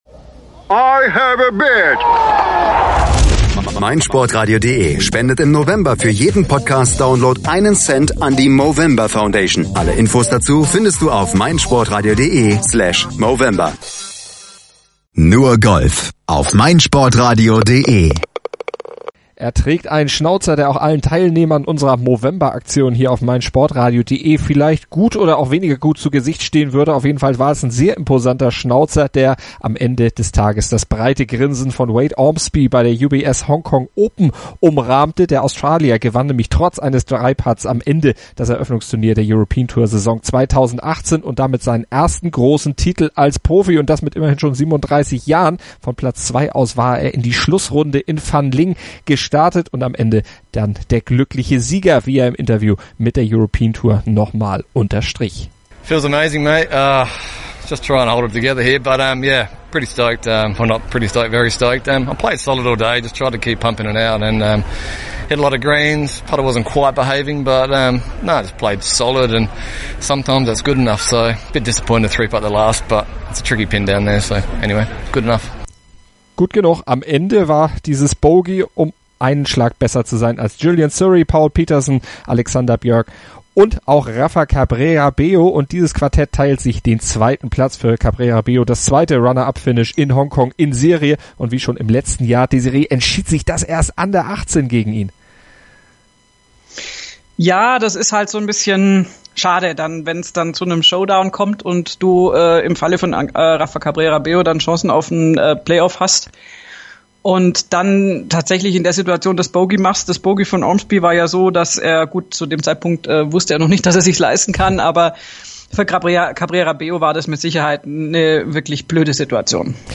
lassen den Sieger von seinem Coup erzählen und von seinem Plan unmittelbar nach dem versenkten Putt zum Turniersieg.